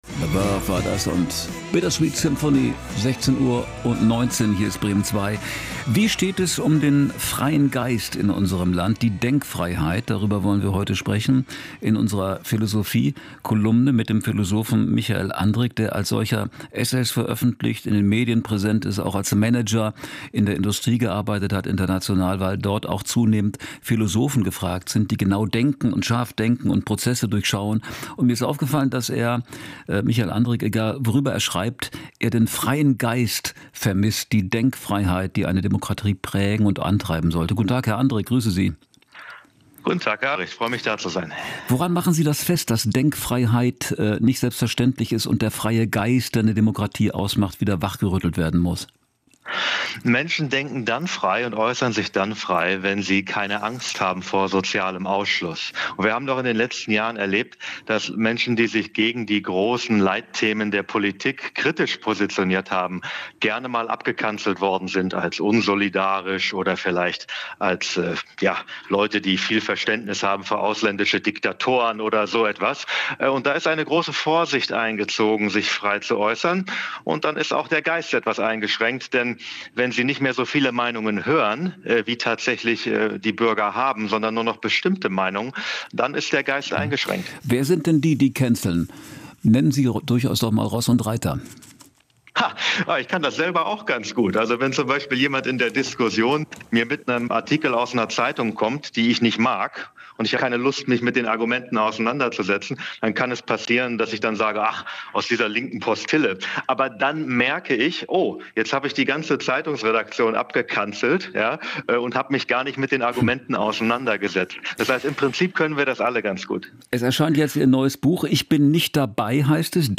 Interview Radio Bremen 2